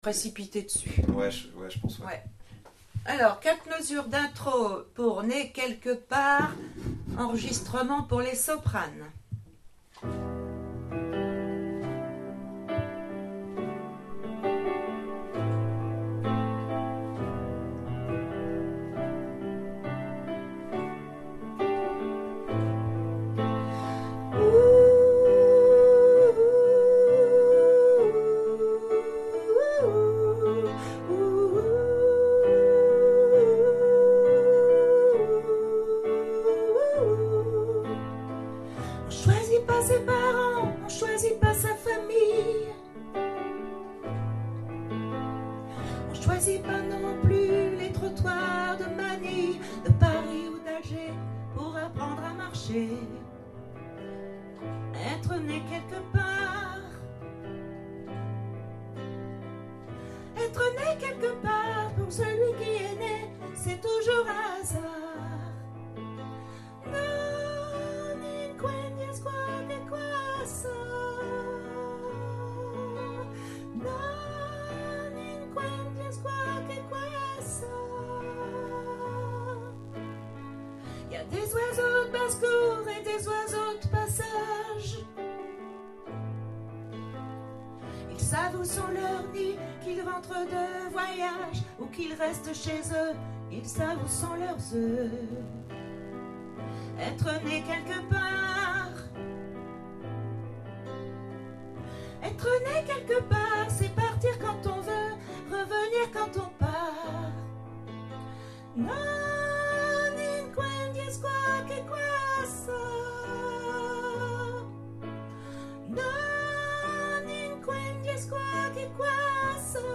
Né Sop piano voix